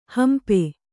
♪ hampe